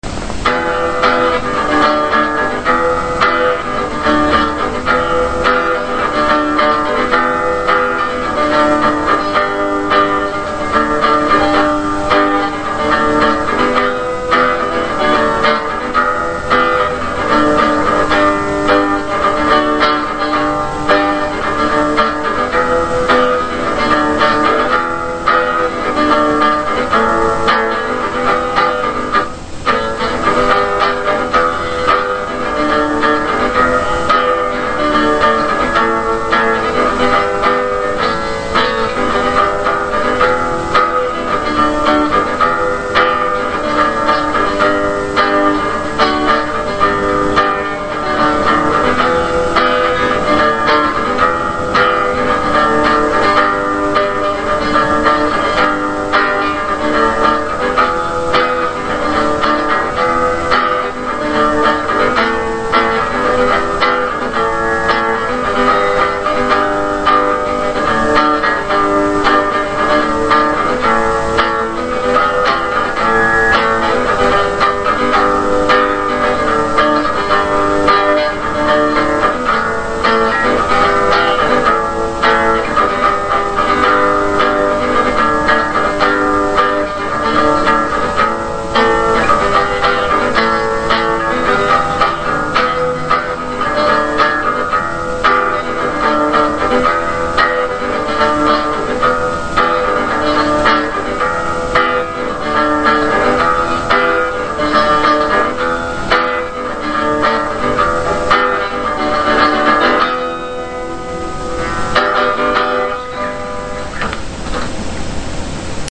Some really noizy and cool wave-files done by me (in first take with fuck-ups and whatevers included):
ACOUSTIC